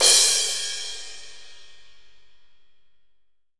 Index of /90_sSampleCDs/AKAI S6000 CD-ROM - Volume 3/Crash_Cymbal1/15-18_INCH_AMB_CRASH
16AMB CRS3-S.WAV